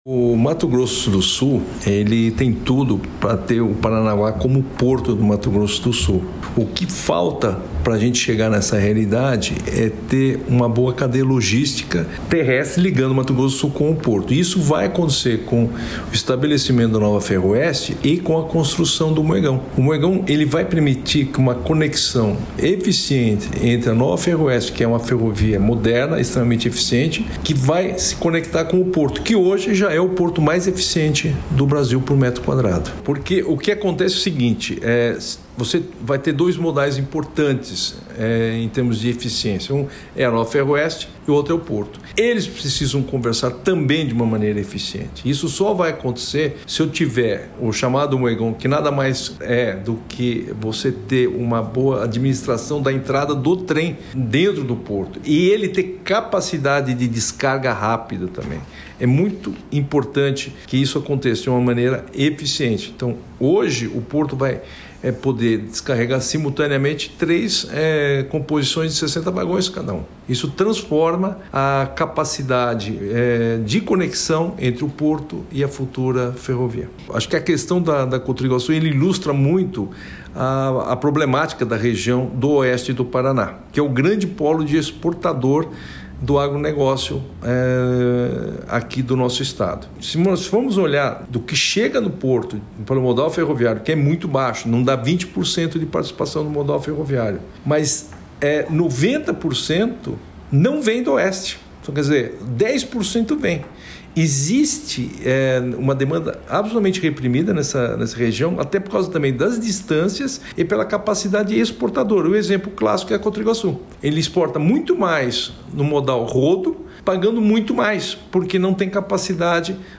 Sonora do coordenador do Plano Estadual Ferroviário do Paraná, Luiz Henrique Fagundes, sobre o ganho de produtividade com a Nova Ferroeste e o Moegão